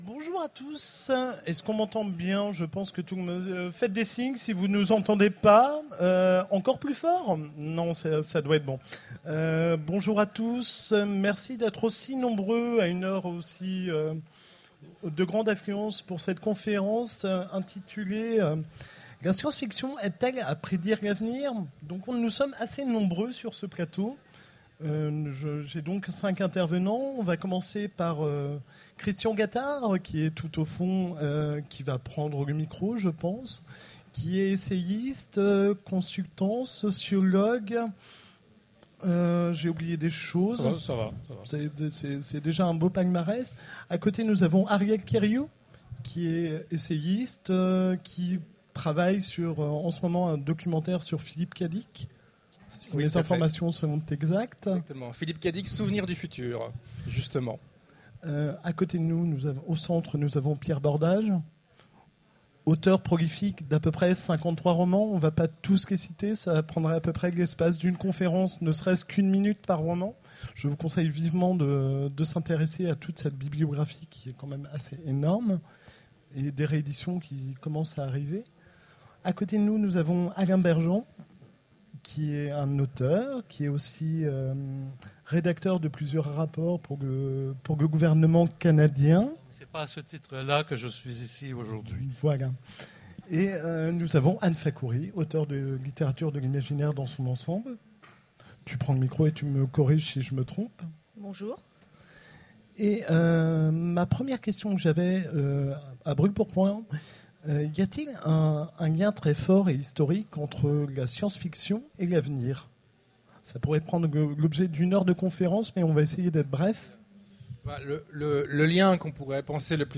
Mots-clés Prospective Conférence Partager cet article